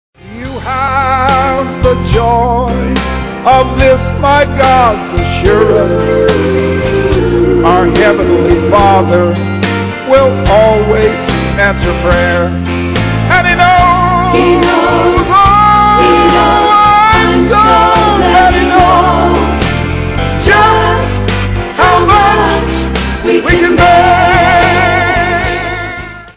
Traditional Gospel Hymn